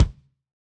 Kick Zion 4.wav